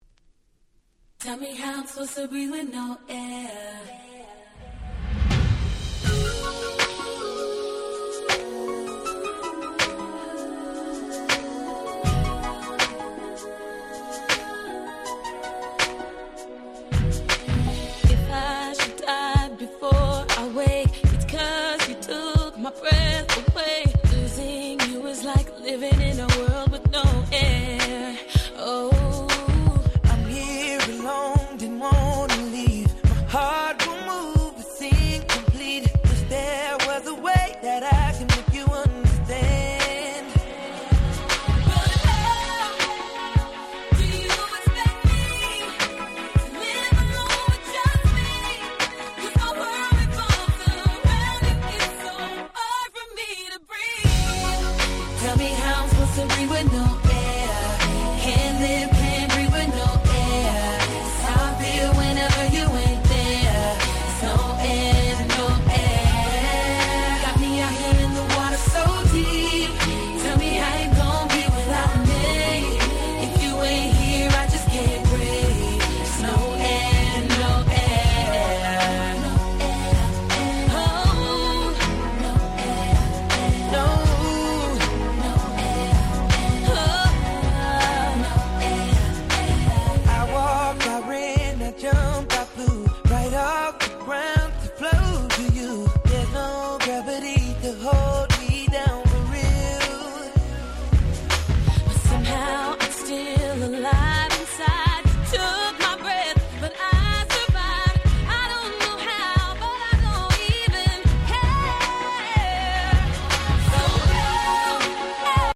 07’ Super Hit R&B !!